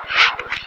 Common EVP Phrases
Are Phrases We Often Hear When Recording EVP